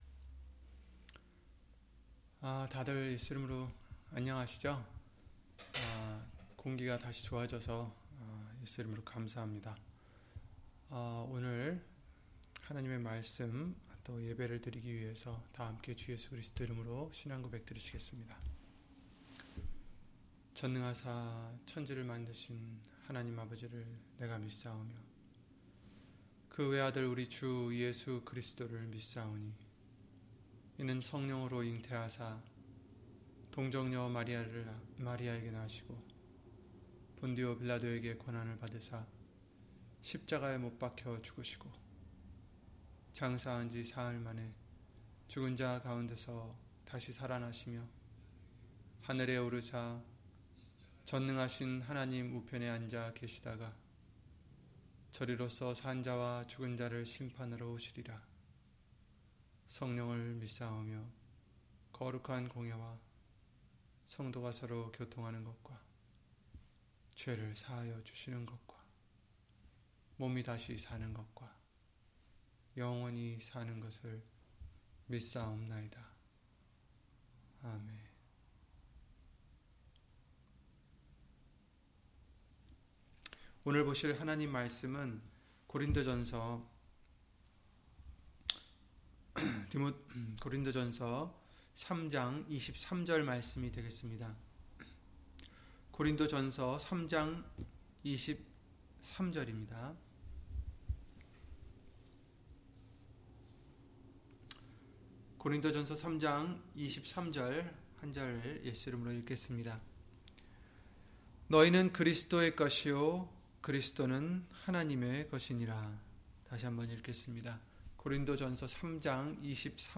고린도전서 3장 23절 [우리는 그리스도의 것] - 주일/수요예배 설교 - 주 예수 그리스도 이름 예배당